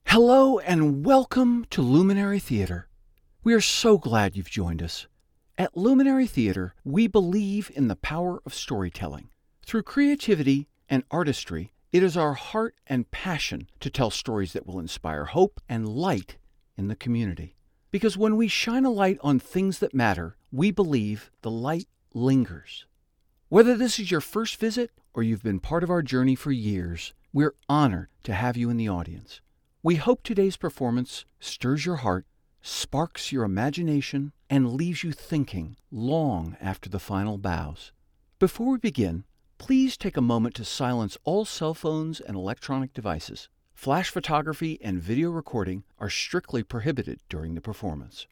Male
Live Announcer
Theatre Performance Intro
Words that describe my voice are articulate, sincere, narrator.
0109Welcome_for_a_Theatre.mp3